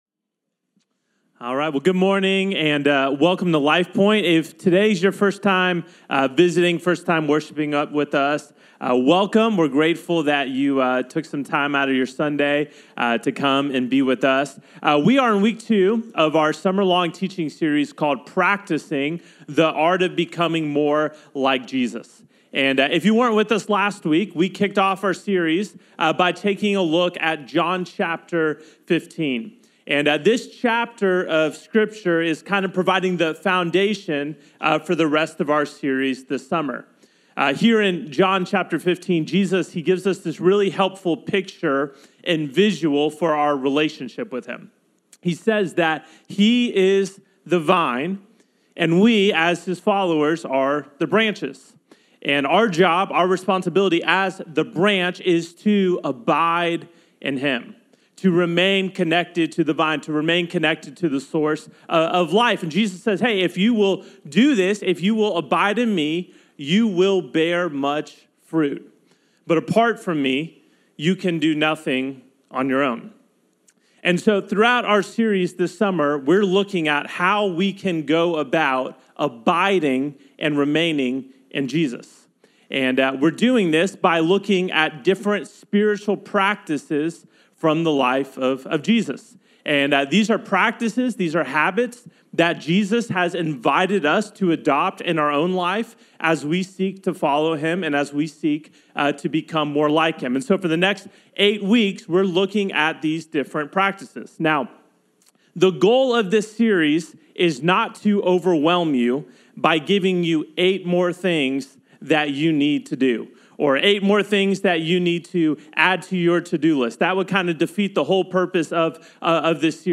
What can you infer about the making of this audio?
service+june+22nd.mp3